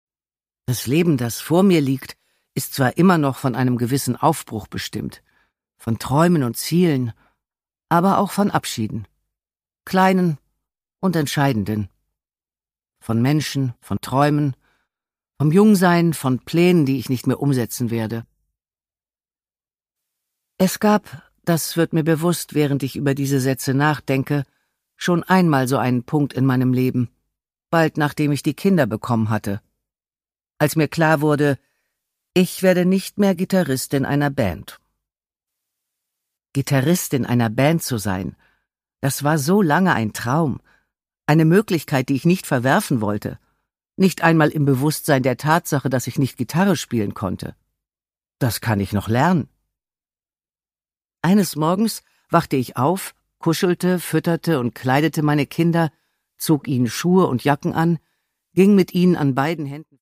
Produkttyp: Hörbuch-Download
Gelesen von: Nina Petri